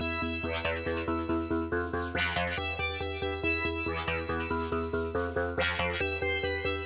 FX,SFX / FILLS / ONESHOTS / etc for dubstep/glitchhop/etc " 较软的汽车尖叫声
Tag: 路过 种族 驱动器 发出刺耳的声音 汽车 驾车通过 驾驶 通过 交通 汽车 城市 公路 车辆 道路 轮胎 传递通过 经过 速度 传球 回响贝斯 街道 woosh 划痕 回响贝斯